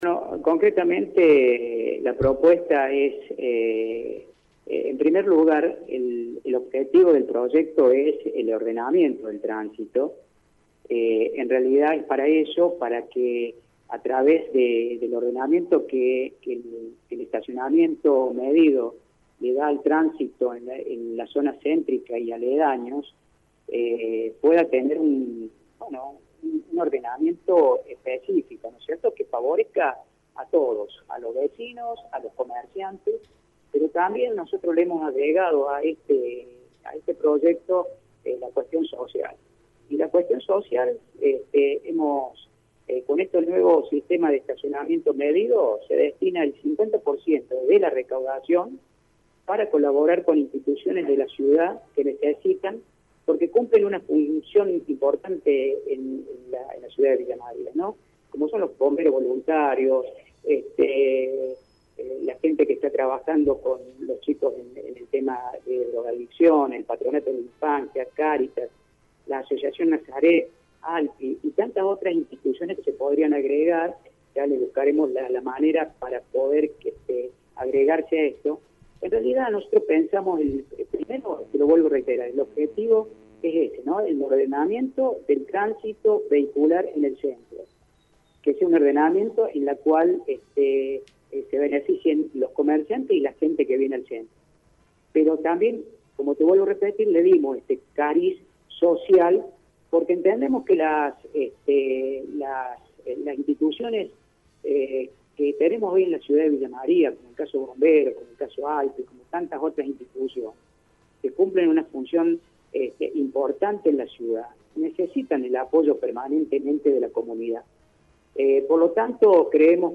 Daniel López, uno de los ediles que elevó la propuesta, habló con Radio Show.